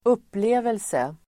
Uttal: [²'up:le:velse]